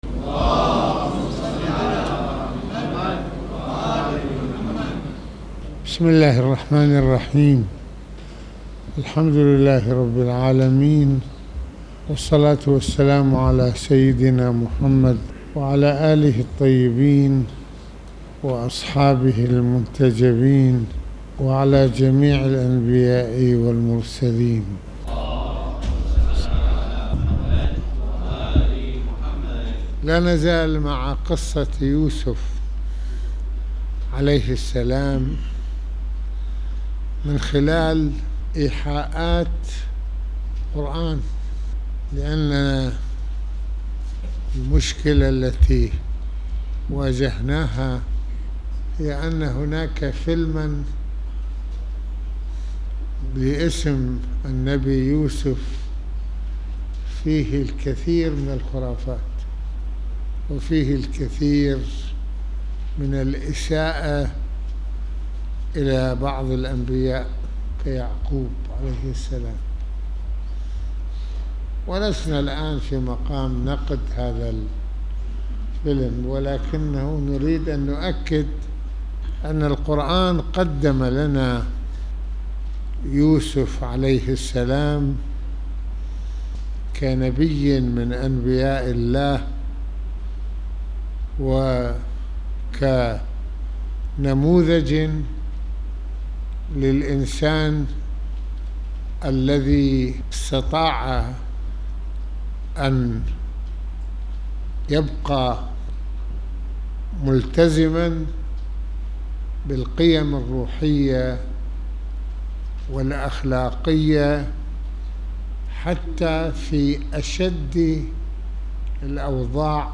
خطبة الجمعة المكان : مسجد الإمامين الحسنين (ع)